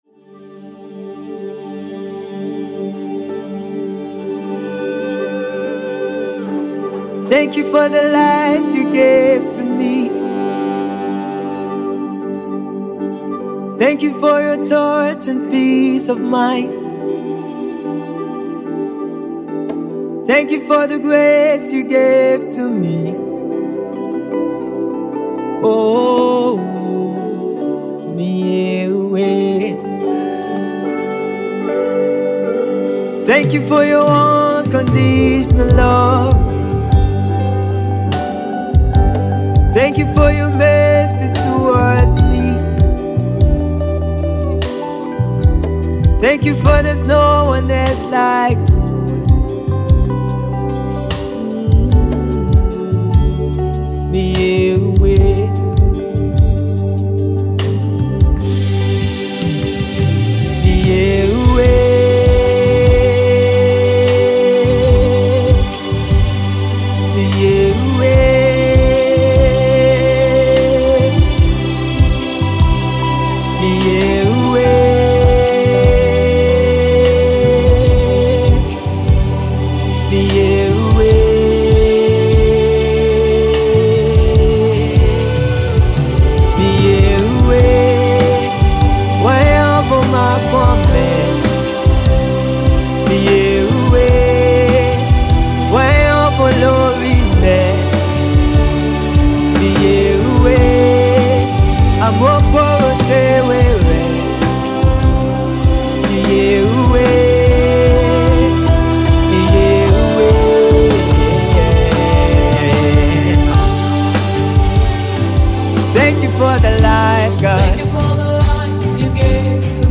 intense worship song